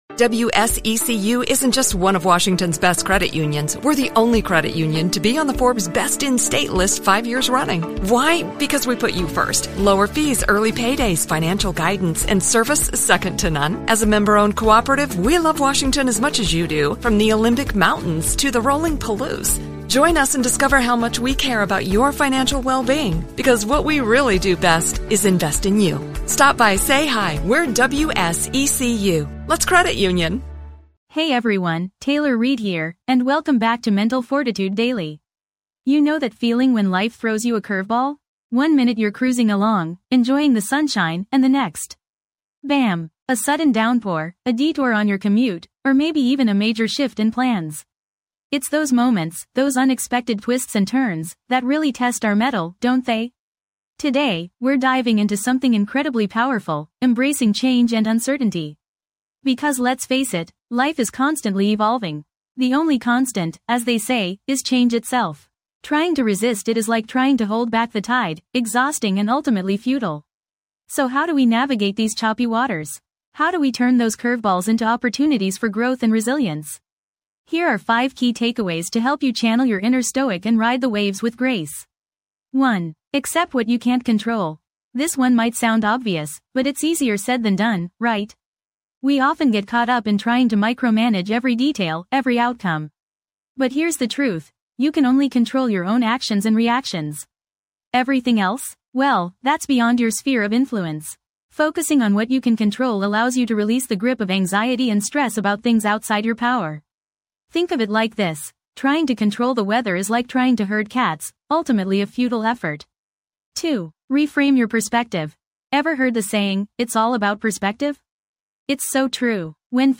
Self-Improvement Personal Development Mindfulness & Meditation Mental Health Inspirational Talks
This podcast is created with the help of advanced AI to deliver thoughtful affirmations and positive messages just for you.